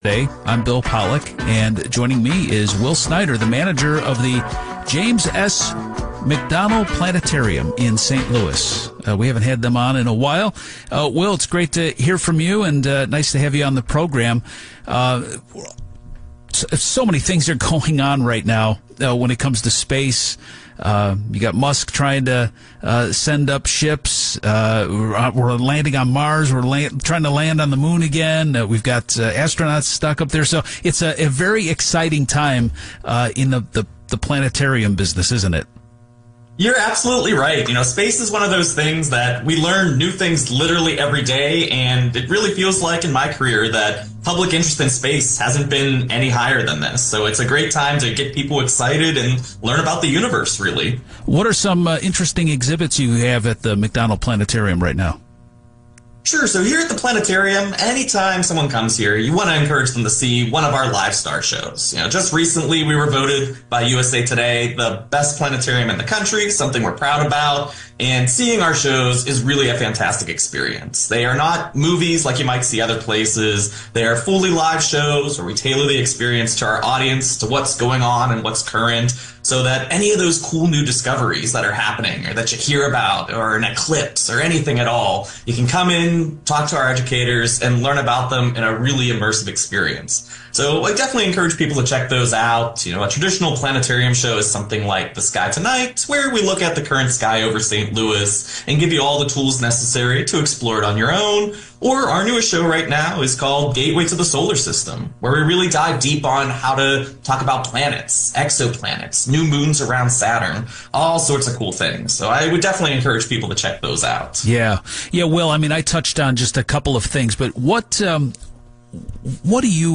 speaks with the Missourinet Radio Network about the McDonnell Planetarium